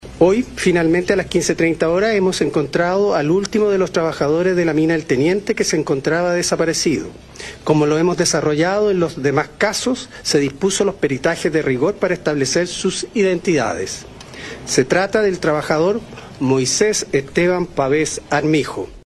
Escuchemos el anuncio  del Fiscal Regional Aquiles Cubillos en el momento que hizo el anuncio del hallazgo del último minero